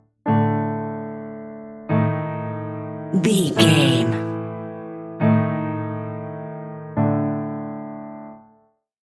Aeolian/Minor
scary
ominous
dark
suspense
eerie
short stinger
short music instrumental
horror scene change music